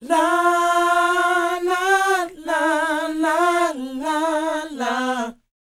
NA-NA A#A -R.wav